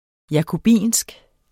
Udtale [ jɑkoˈbiˀnsg ]